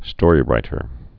(stôrē-rītər)